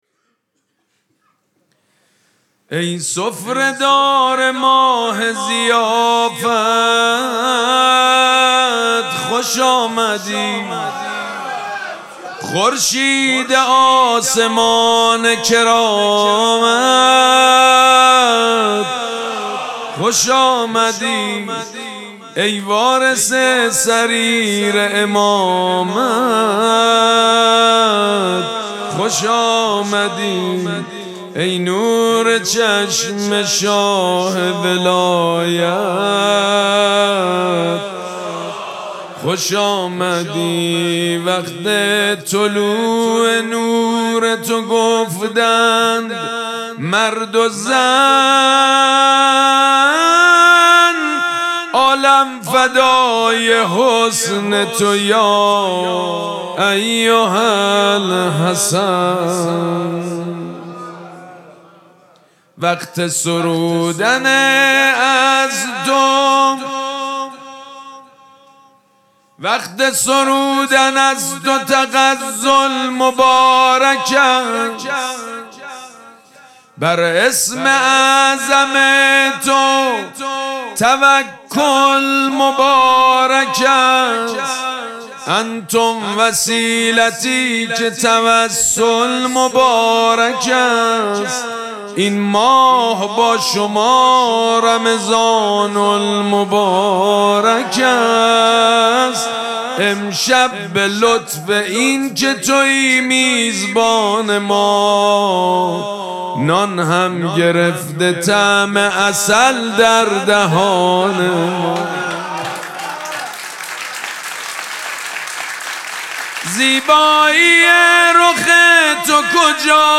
مراسم جشن شام ولادت امام حسن مجتبی(ع)
شعر خوانی
مداح
حاج سید مجید بنی فاطمه